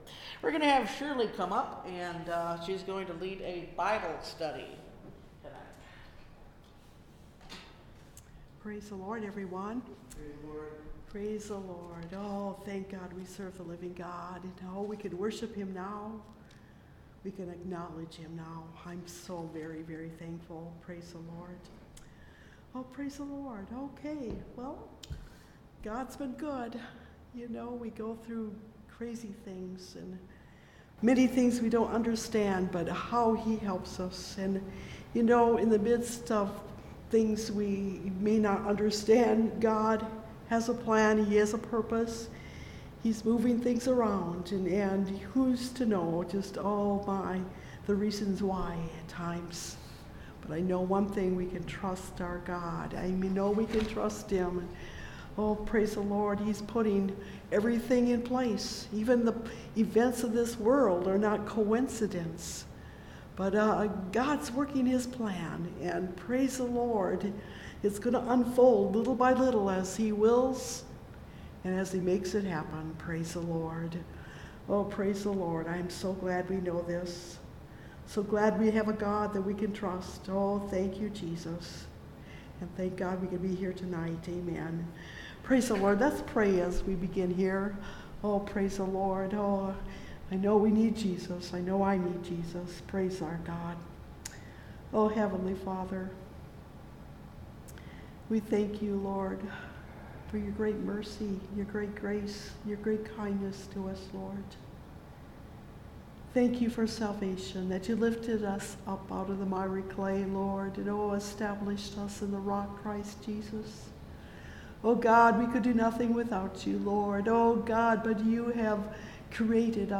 Out of the Abundance of the Heart (Message Audio) – Last Trumpet Ministries – Truth Tabernacle – Sermon Library